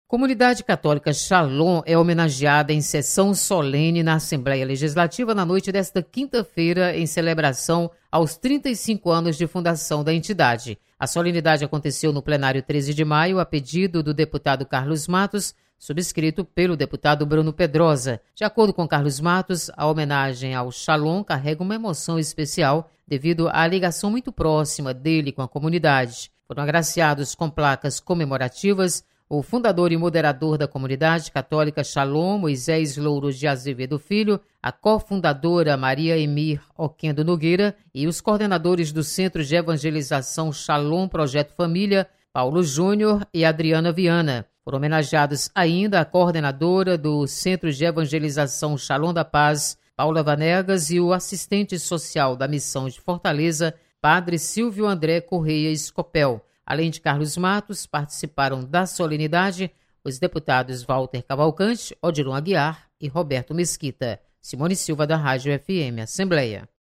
Você está aqui: Início Comunicação Rádio FM Assembleia Notícias Plenário